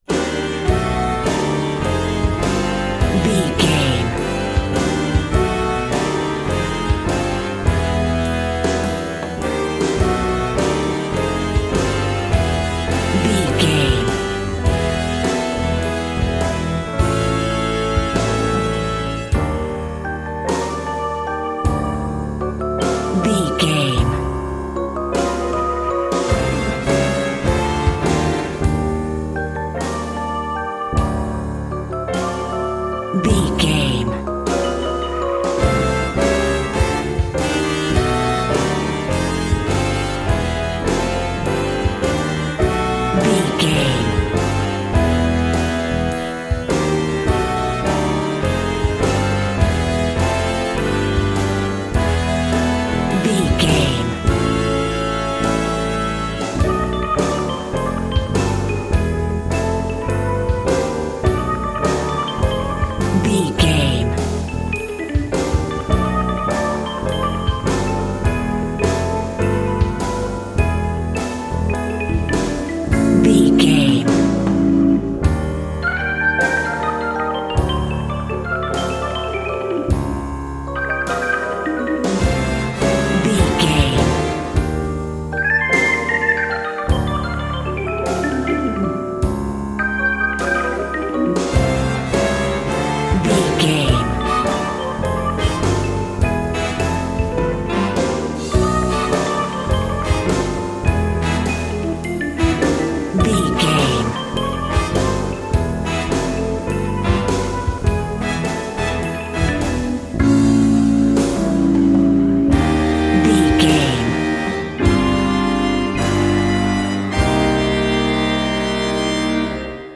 Aeolian/Minor
groovy